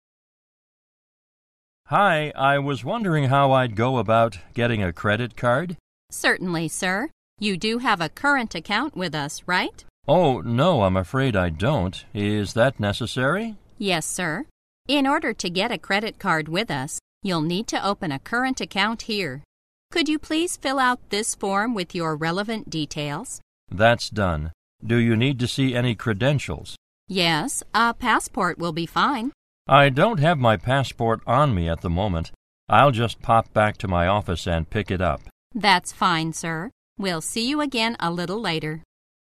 在线英语听力室银行英语情景口语 第3期:现金业务 开户情景(3)的听力文件下载, 《银行英语情景口语对话》,主要内容有银行英语情景口语对话、银行英语口语、银行英语词汇等内容。